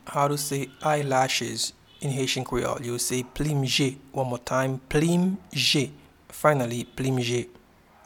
Pronunciation and Transcript:
Eyelashes-in-Haitian-Creole-Plim-je.mp3